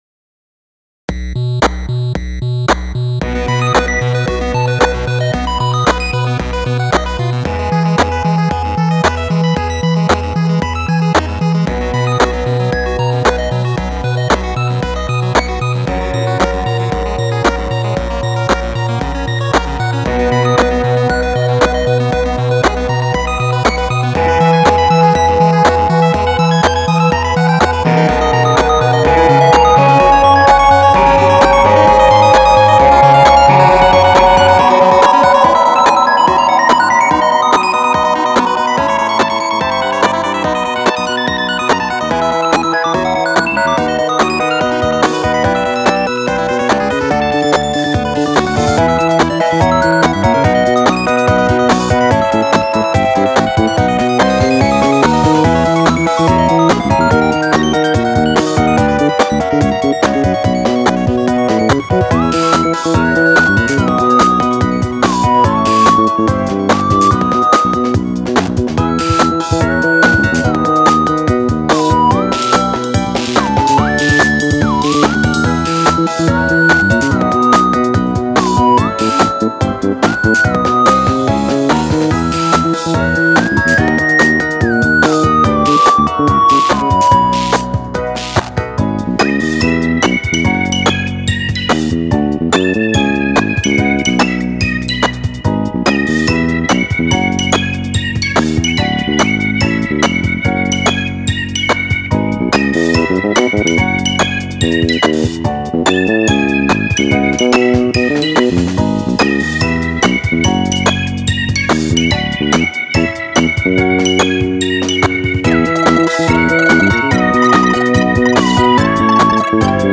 This is fun music, with a mixture of 8bit NES sounds and regular instruments. A moving bass line. The melody is simple, but the background beat is rather complex. I think the synth sound I made in the middle sounds a bit like a Dinosasur...